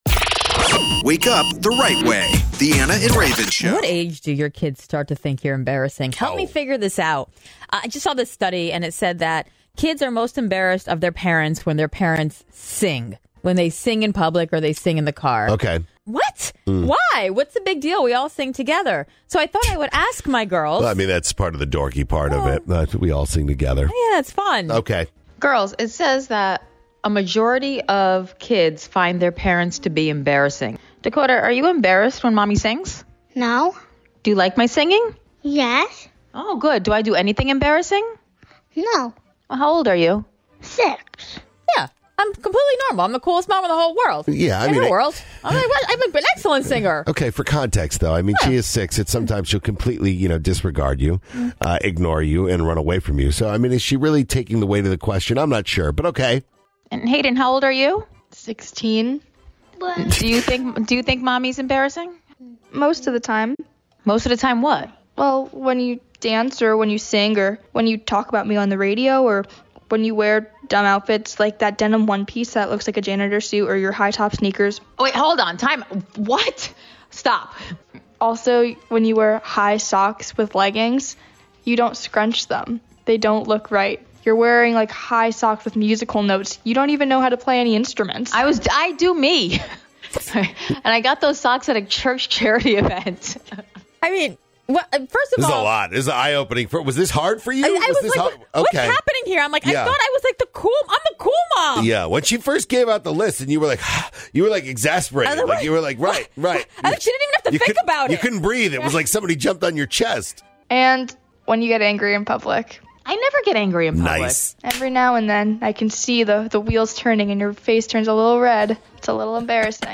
Broadcasting weekday mornings from 5:00am and heard on 75 stations across America.